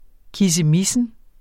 kissemissen substantiv, fælleskøn Udtale [ kisəˈmisən ] Betydninger det at kissemisse Synonym kissemisseri Rapportér et problem fra Den Danske Ordbog Den Danske Ordbog .